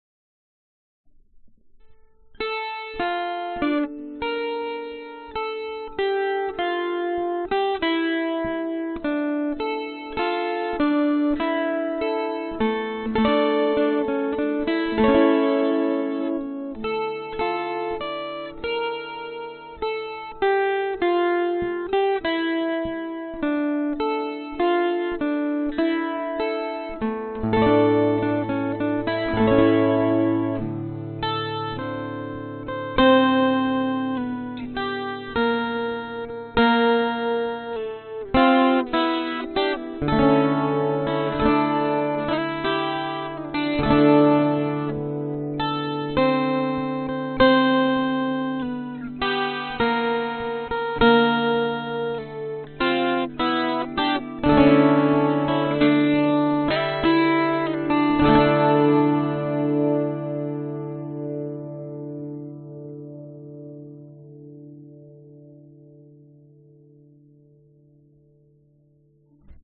延长吉他即兴演奏的干茎在下载中...
Baroque 吉他 12弦 即兴演奏